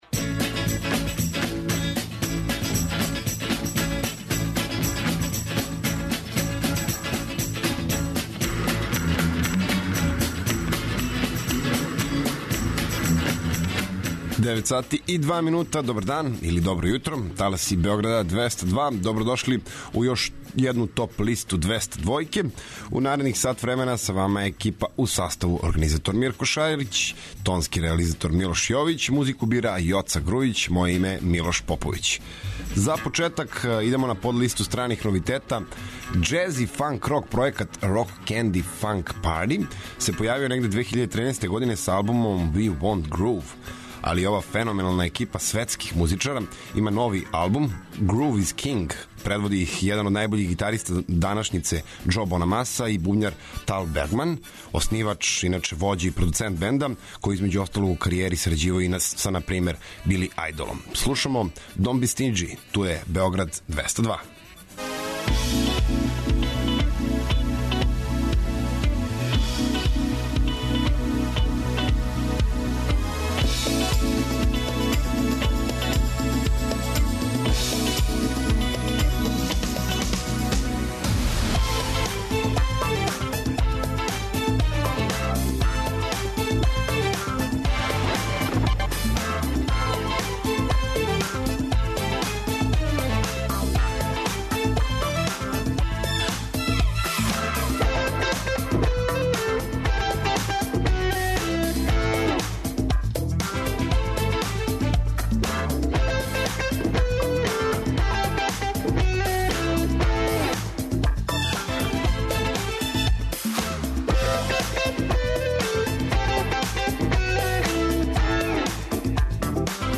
Емисија садржи више различитих жанровских подлиста.